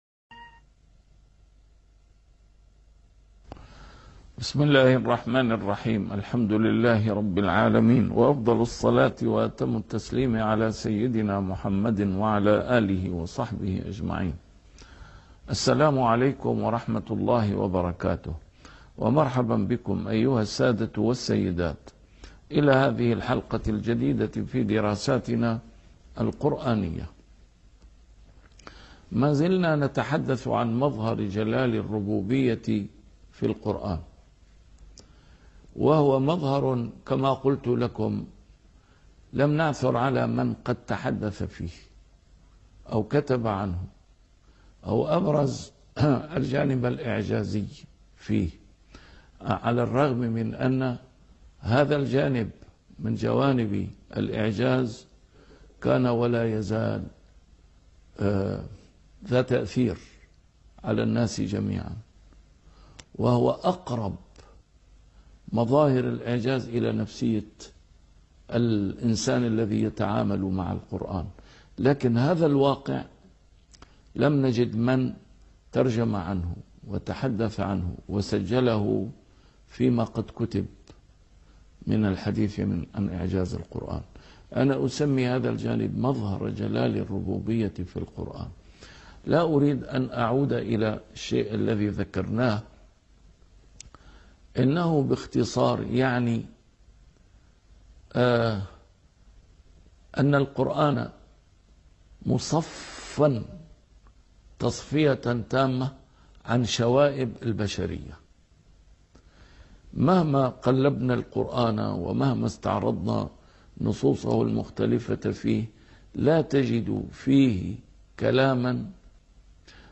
A MARTYR SCHOLAR: IMAM MUHAMMAD SAEED RAMADAN AL-BOUTI - الدروس العلمية - الجديد في إعجاز القرآن الكريم - 14- الجديد في إعجاز القرآن الكريم |أمثلة على مظهر جلال الربوبية ؟